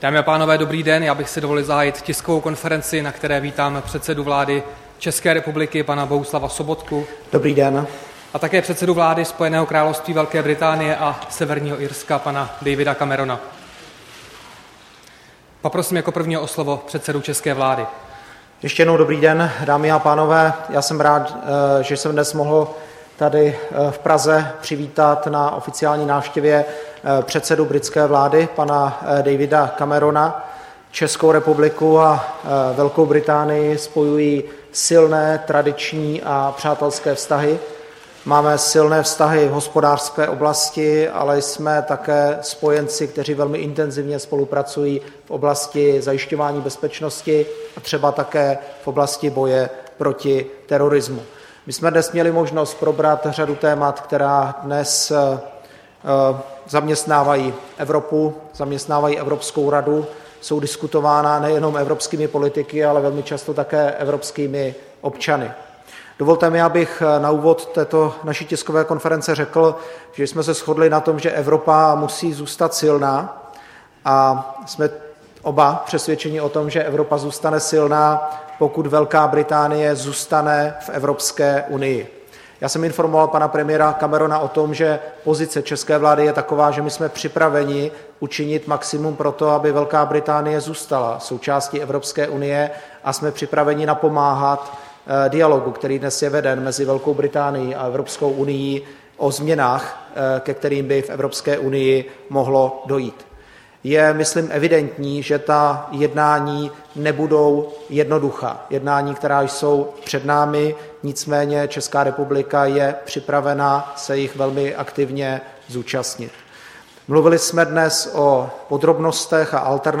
Tisková konference po setkání premiéra Bohuslava Sobotky s předsedou vlády Velké Británie Davidem Cameronem 22. ledna 2016